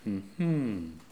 ajout des sons enregistrés à l'afk
hum-reflexion_02.wav